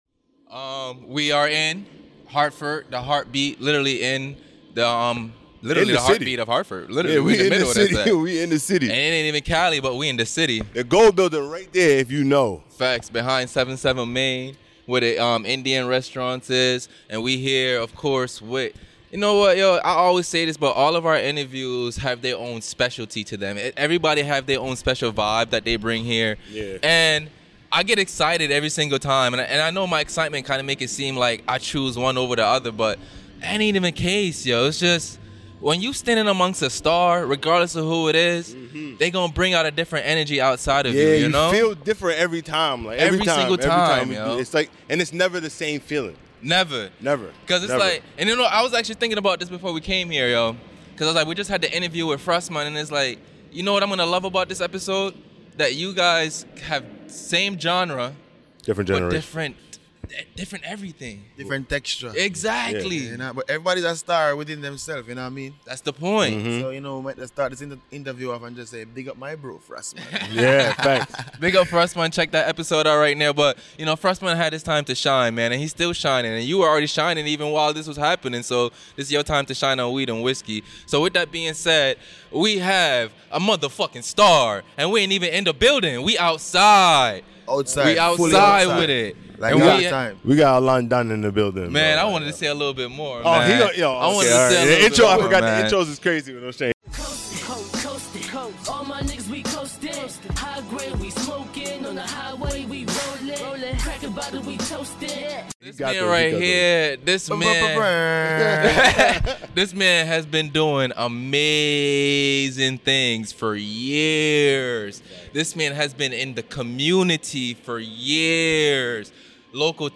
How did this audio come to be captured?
This episode took place in the heart of DOWNTOWN HARTFORD. WE WAS OUTSIDE AT THE HIGH OF DOWNTOWN, people walking, cars driving, birds chirping, ventilation system running.